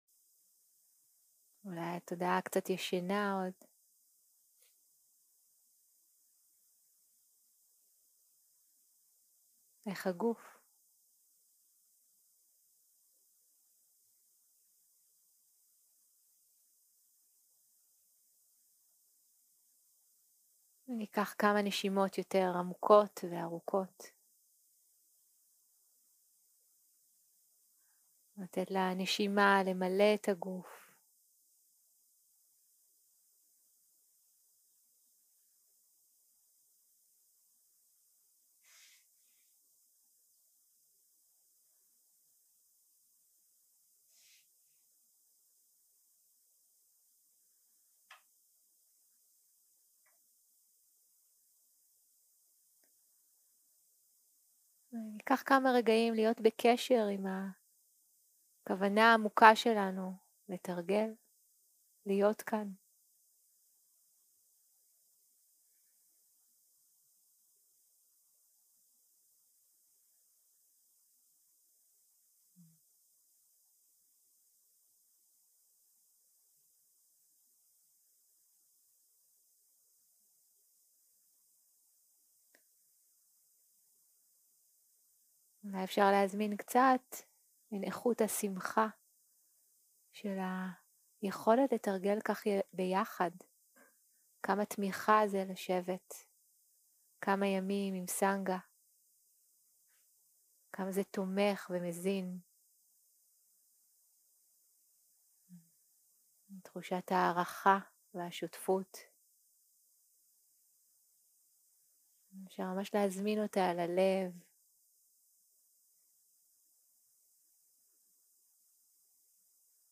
יום 3 - הקלטה 5 - צהרים - מדיטציה מונחית
סוג ההקלטה: מדיטציה מונחית